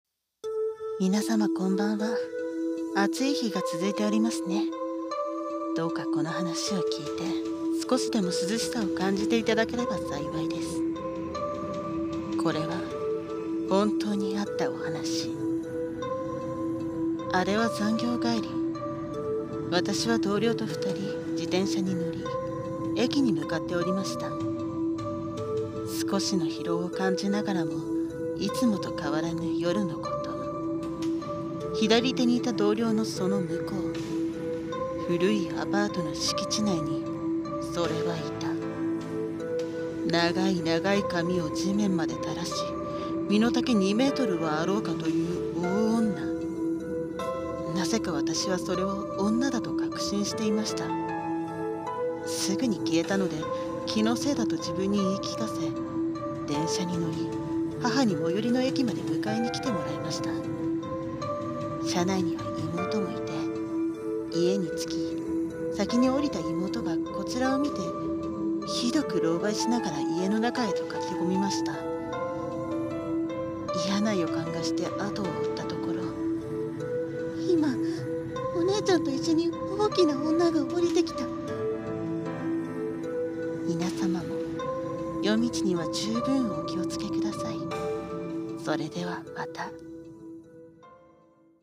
【怪談】